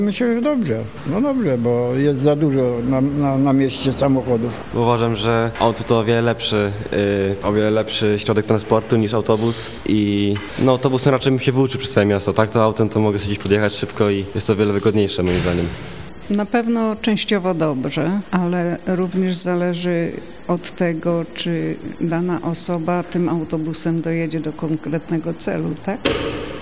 W Stargardzie bilet dziś zastępuje dowód rejestracyjny pojazdu. Zapytaliśmy mieszkańców Stargardu, co sądzą o takim rozwiązaniu:
sonda-autobus.mp3